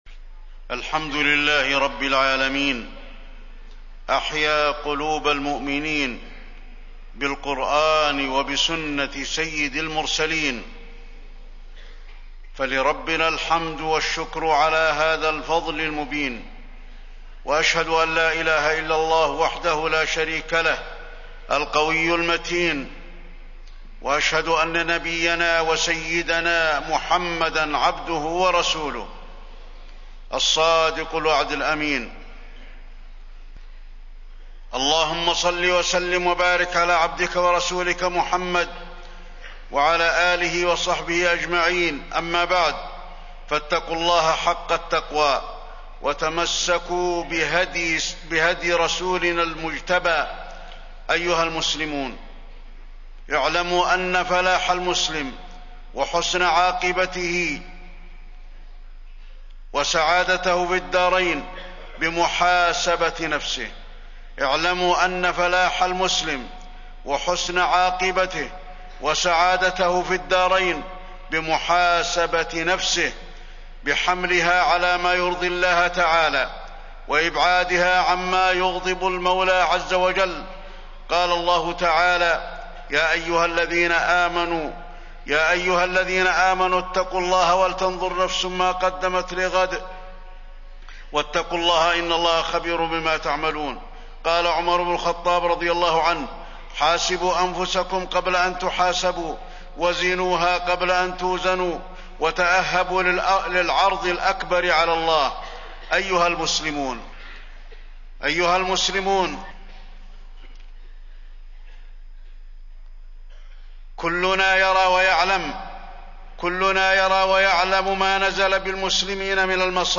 تاريخ النشر ١١ محرم ١٤٣٢ هـ المكان: المسجد النبوي الشيخ: فضيلة الشيخ د. علي بن عبدالرحمن الحذيفي فضيلة الشيخ د. علي بن عبدالرحمن الحذيفي السعادة في محاسبة النفس